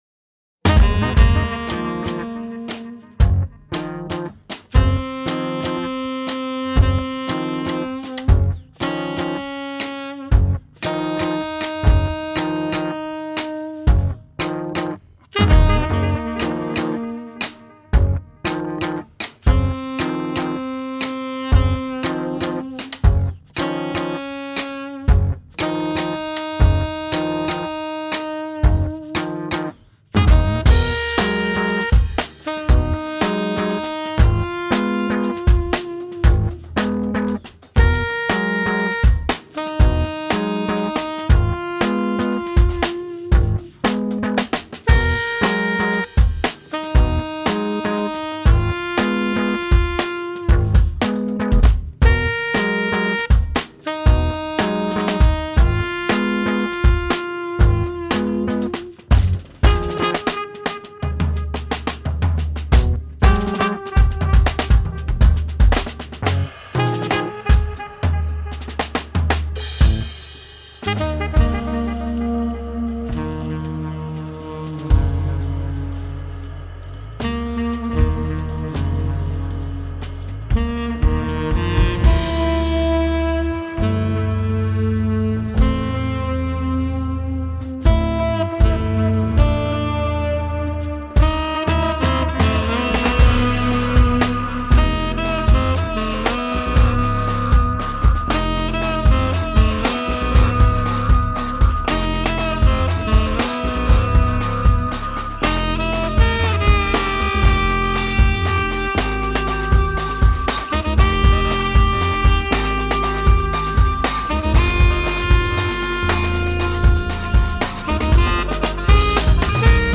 a jazz power trio for the 21st century
saxophone+effects